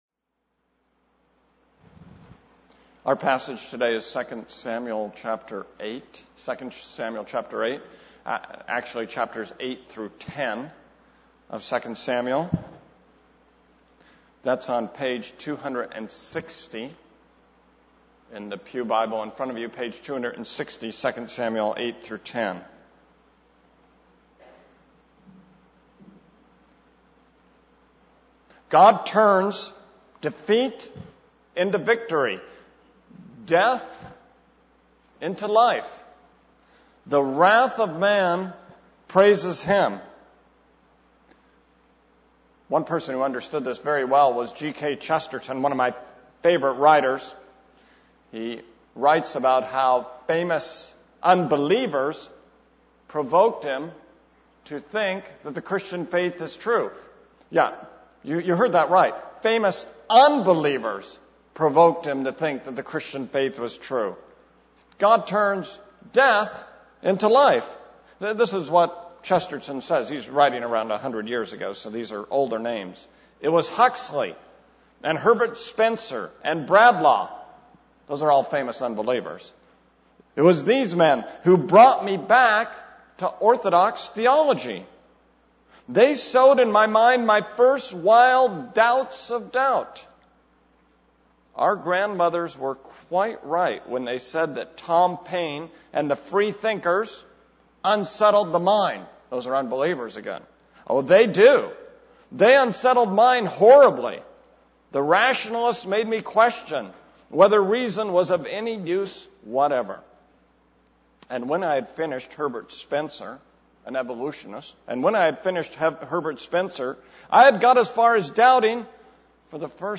This is a sermon on 2 Samuel 8:1-10:19.